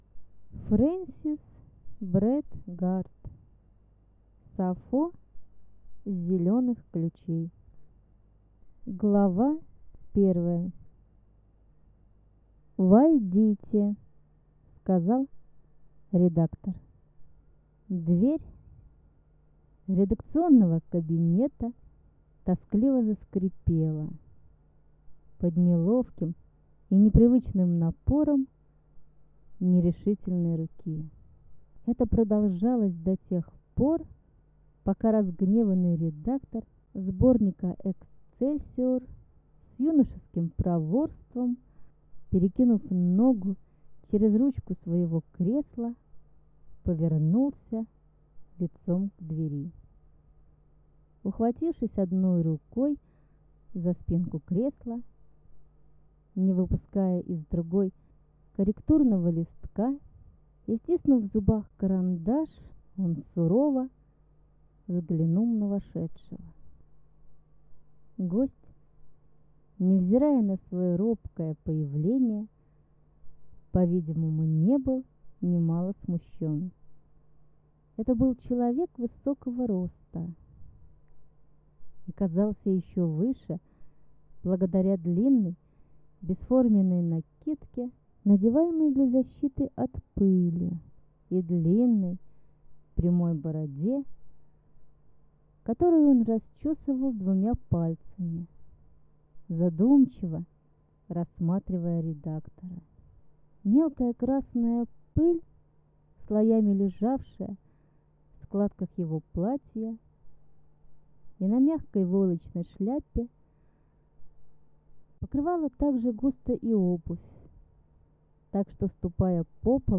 Аудиокнига Сафо с Зеленых Ключей | Библиотека аудиокниг